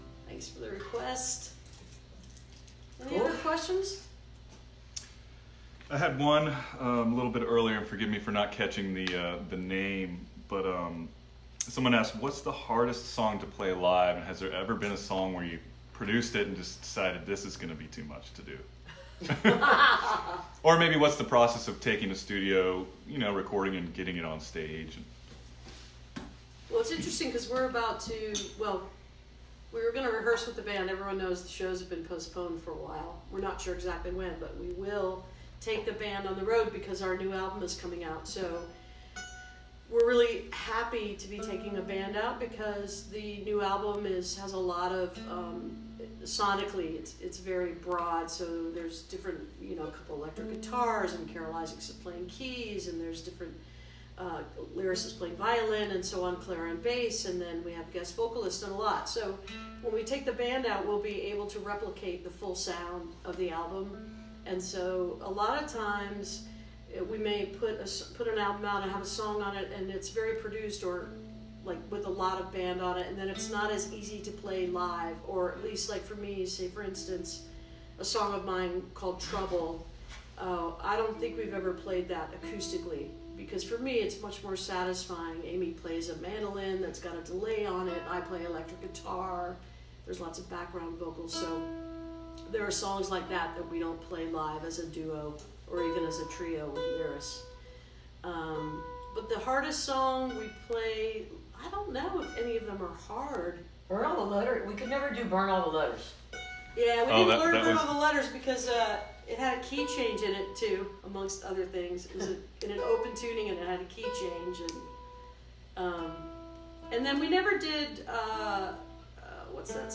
(captured from a facebook live stream)
14. talking with the crowd (4:28)